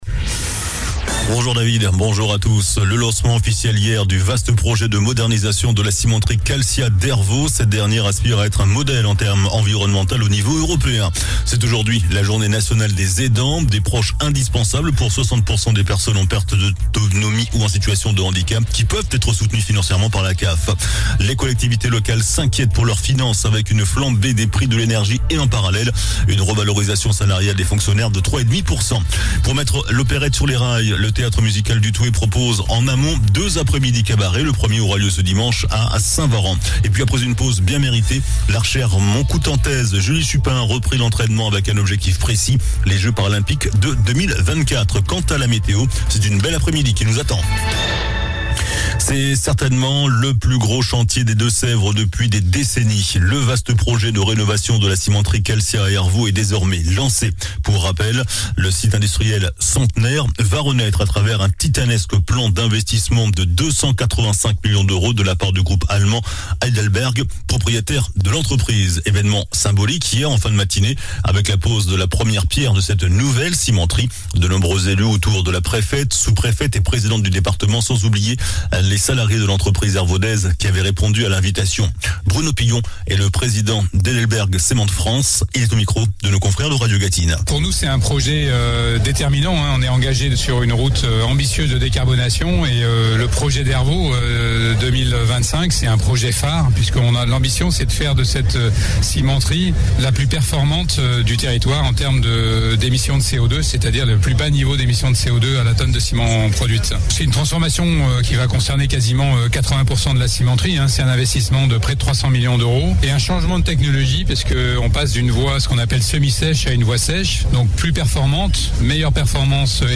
JOURNAL DU JEUDI 06 OCTOBRE ( MIDI )